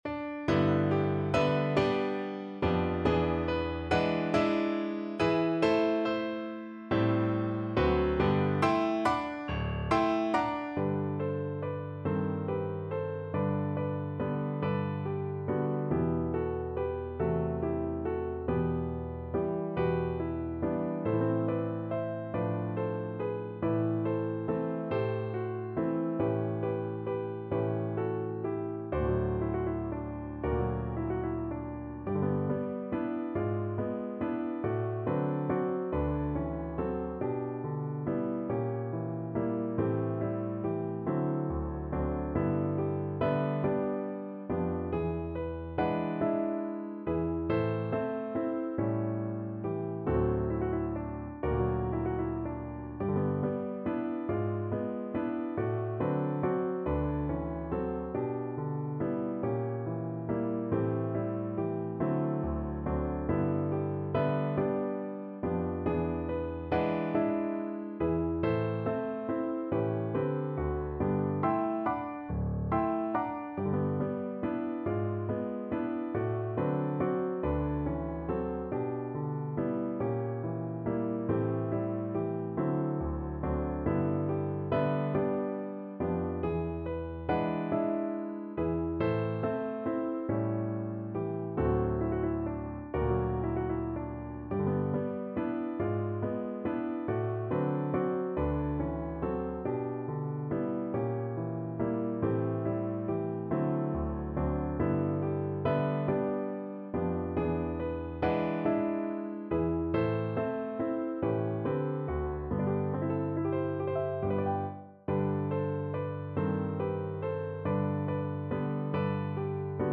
3/4 (View more 3/4 Music)
~ = 140 Tenderly